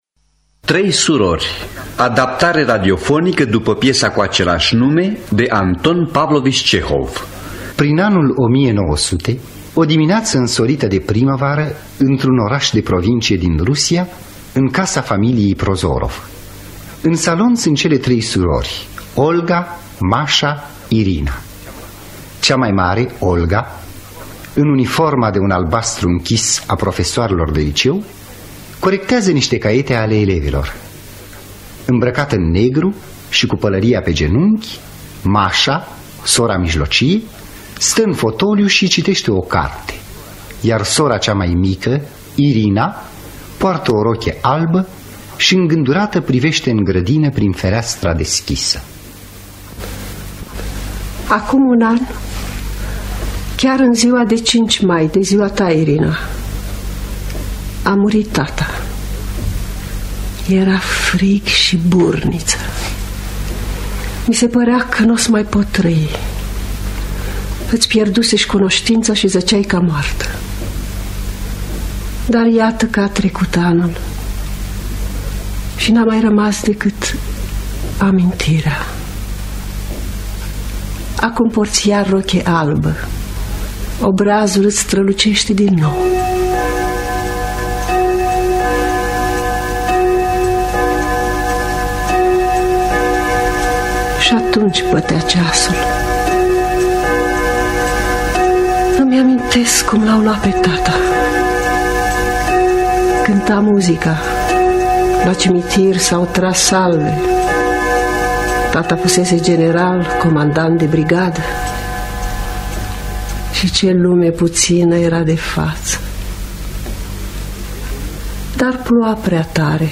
Trei surori de Anton Pavlovici Cehov – Teatru Radiofonic Online